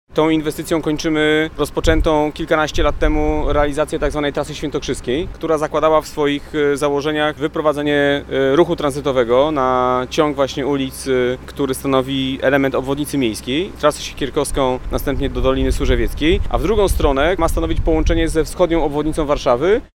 O znaczeniu inwestycji mówi wiceprezydent stolicy Michał Olszewski.